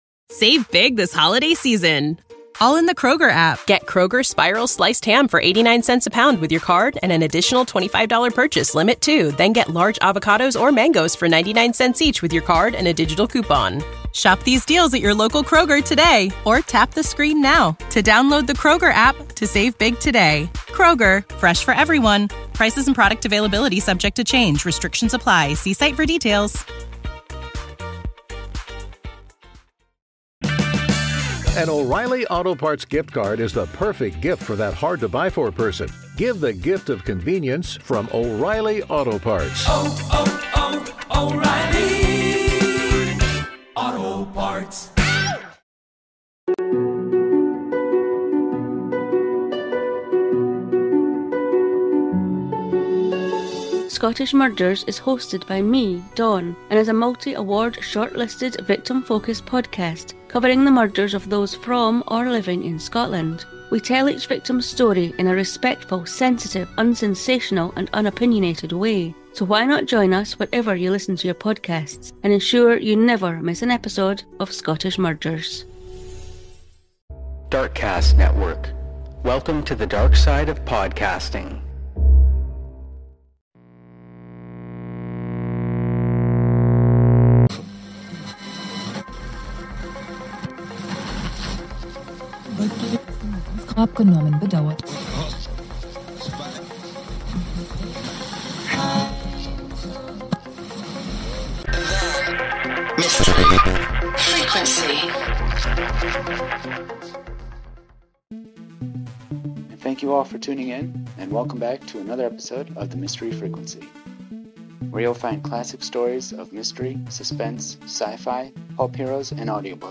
There's more to Old time radio then just detectives, and you'll find a variety of those stories here, in their original form where each episode takes you to another place or time.